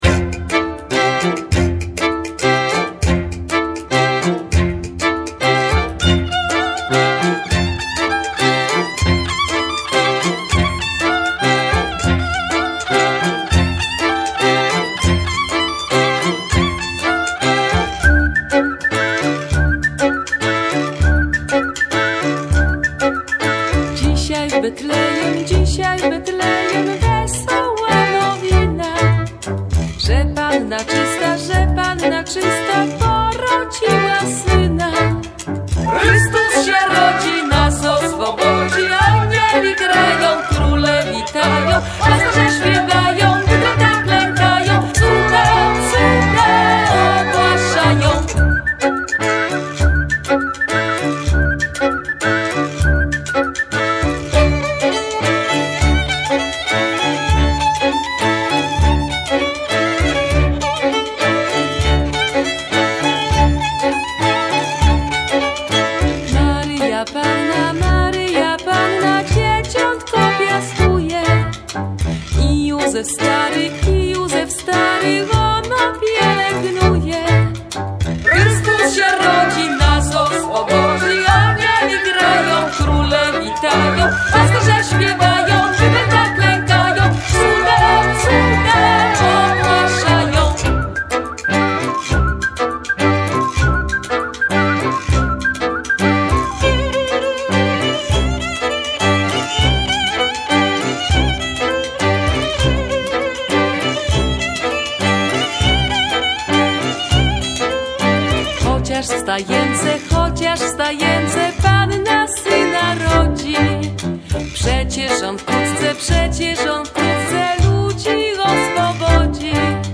instrumenty pasterskie
- kolęda (3,3 Mb)